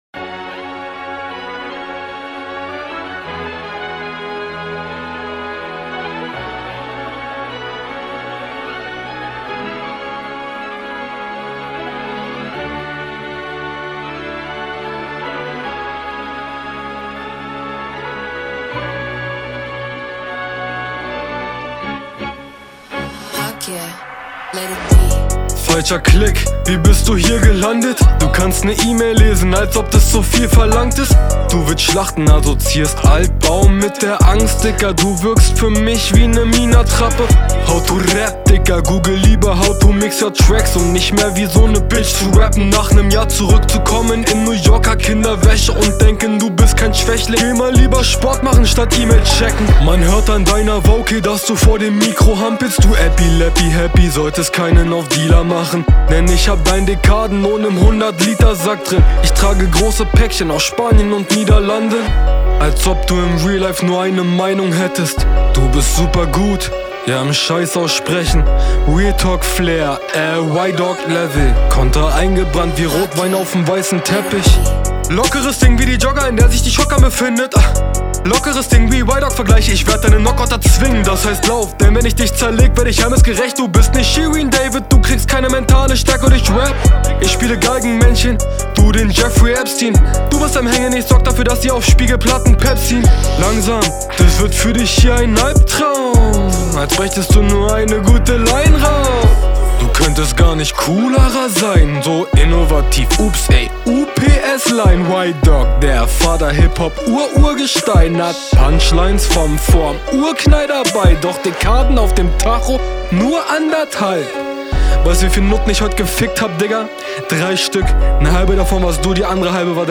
kauf dir mal ein neues mic diese störgeräusche tun echt weh in den ohren